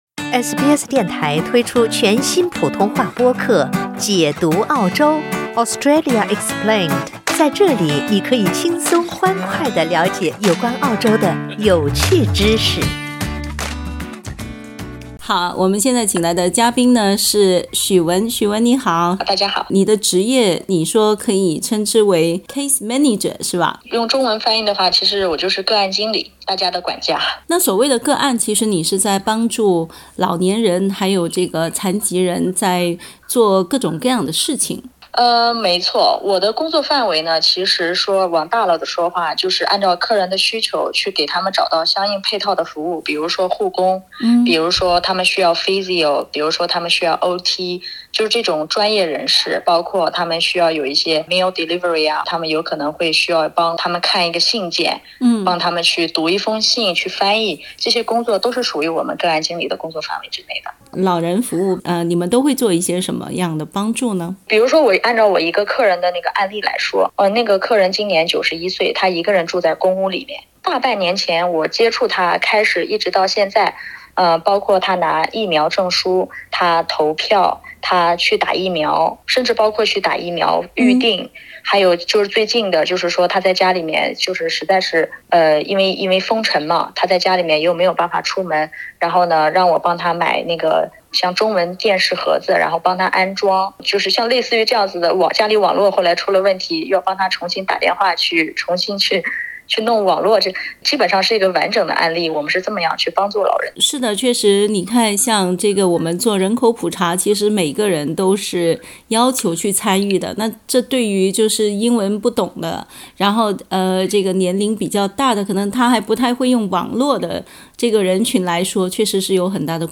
（點擊圖片收聽報道）